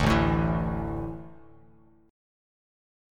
Asus4 chord